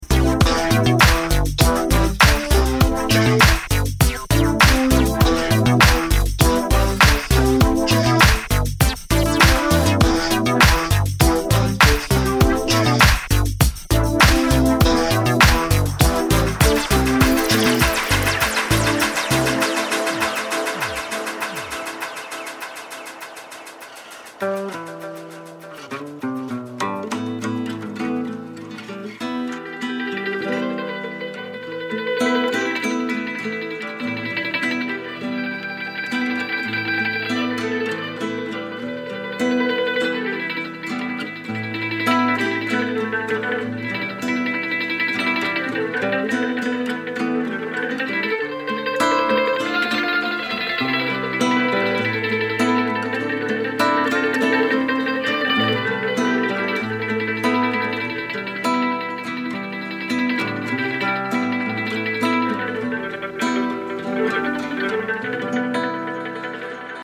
Disco Electro